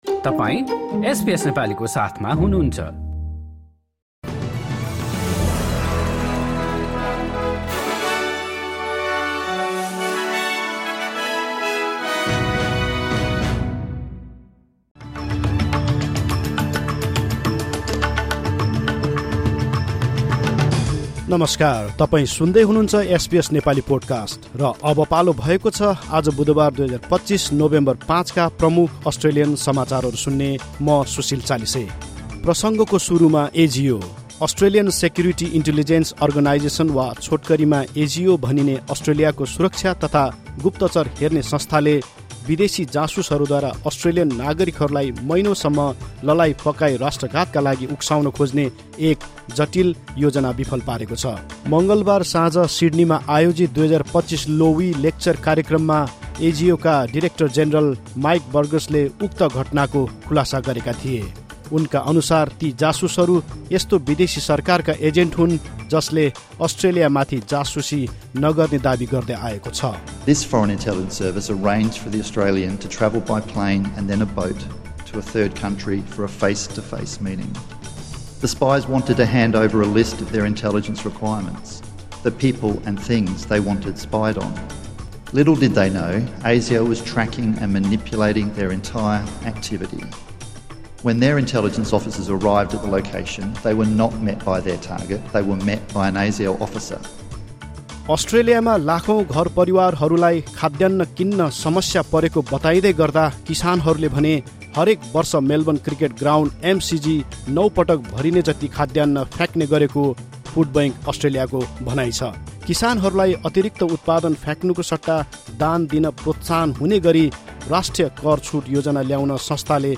एसबीएस नेपाली प्रमुख अस्ट्रेलियन समाचार: बुधवार, ५ नोभेम्बर २०२५